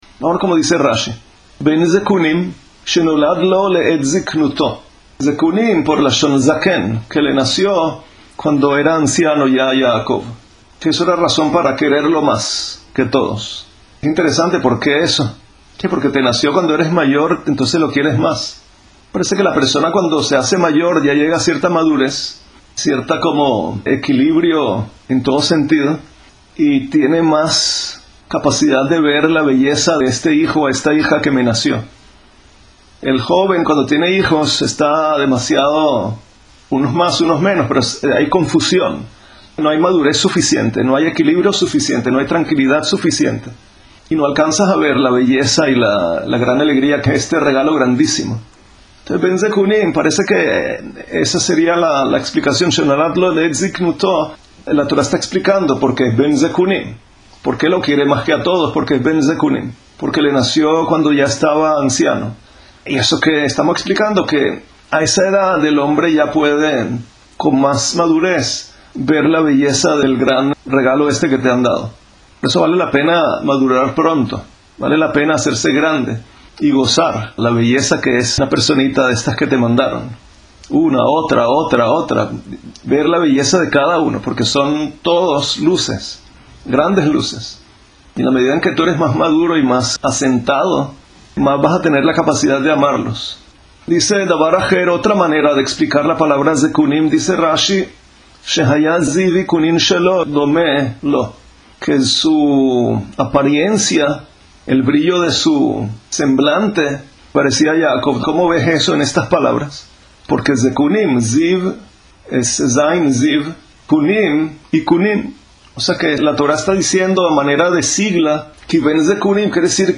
Lección-de-Jinuj-de-la-Parashá-Vayeshev.mp3